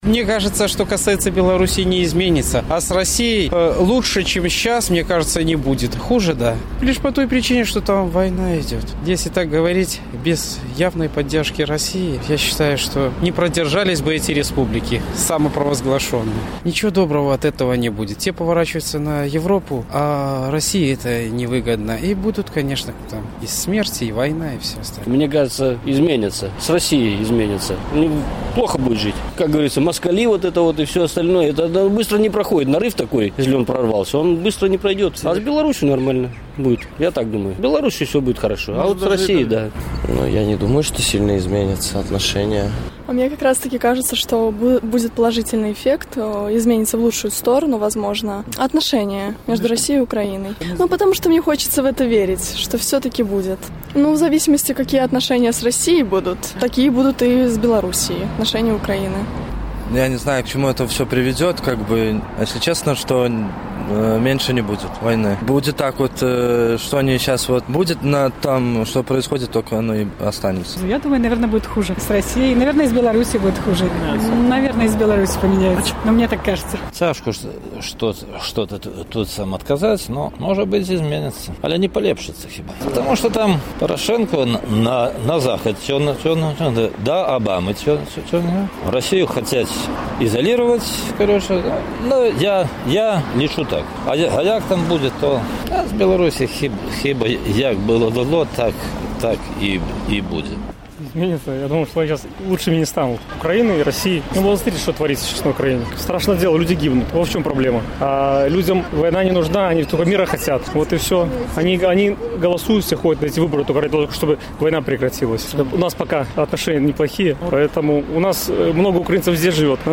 Як гэта паўплывае на дачыненьні паміж Украінай і Расеяй, Украінай і Беларусьсю? З такім пытаньнем наш карэспандэнт зьвяртаўся да гарадзенцаў.